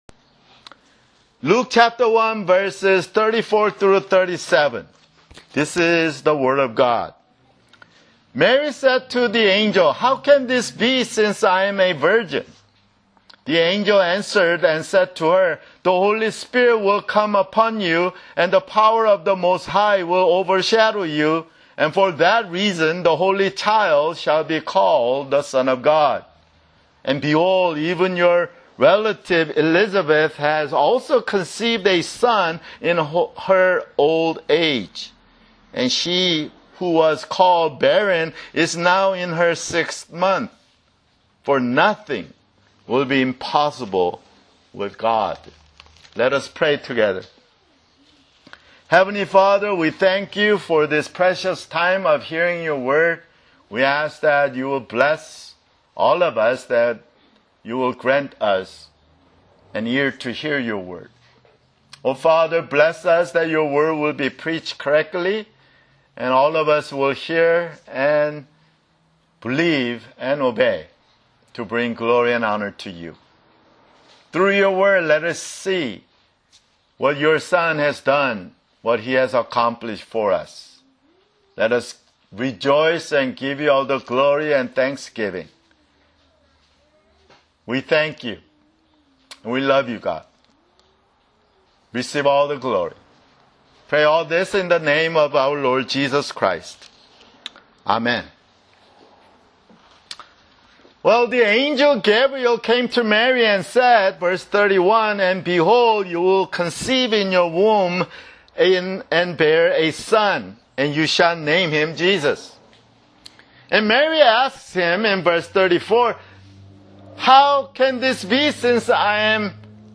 [Sermon] Luke (7)